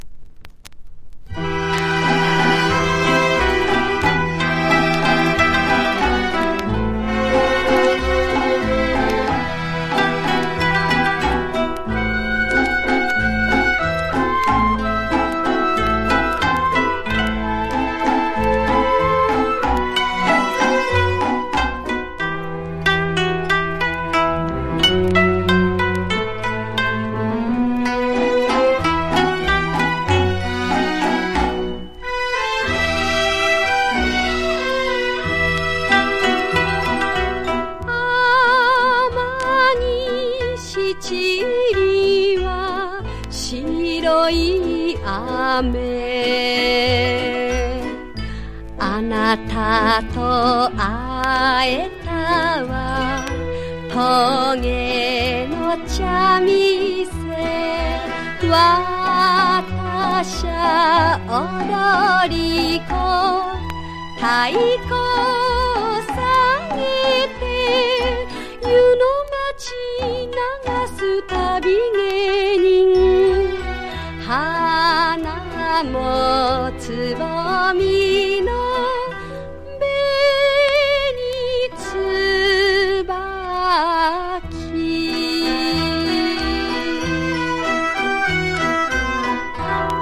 (軽いノイズあり)